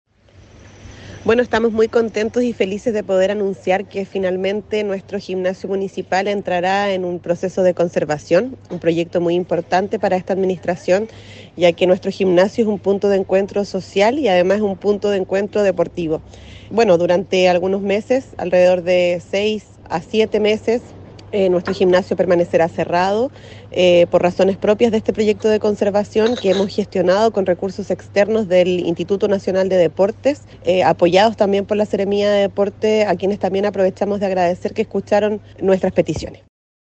Al respecto, la alcaldesa de Curaco de Vélez, Javiera Yáñez, puntualizó que se trata de recinto público que requería de un mejoramiento para entregar óptimas condiciones a toda la comunidad, que lo utiliza no solo en actividades deportivas y físicas, sino que también sociales, comunitarias, artísticas y culturales: